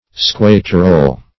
squaterole - definition of squaterole - synonyms, pronunciation, spelling from Free Dictionary Search Result for " squaterole" : The Collaborative International Dictionary of English v.0.48: Squaterole \Squat"er*ole\ (-[~e]r*[=o]l), n. (Zool.) The black-bellied plover.